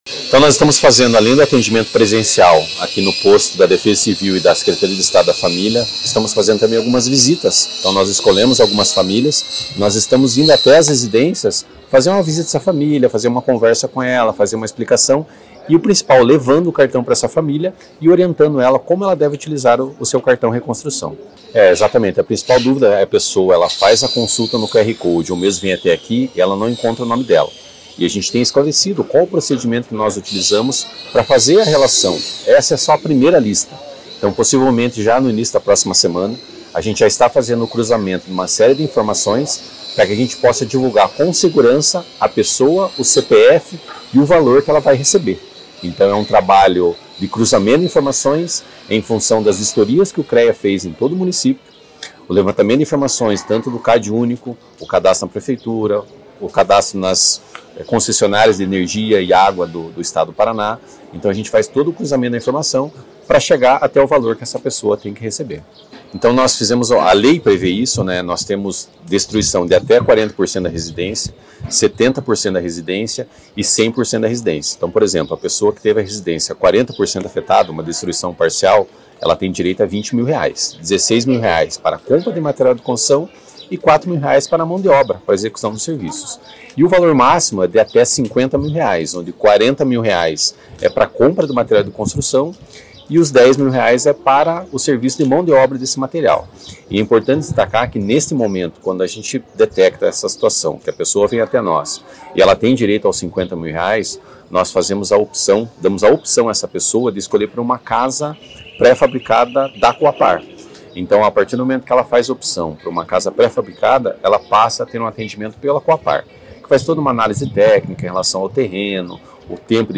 Sonora do coordenador executivo da Defesa Civil Estadual, Ivan Fernandes, trabalho de entrega dos Cartões Reconstrução em Rio Bonito do Iguaçu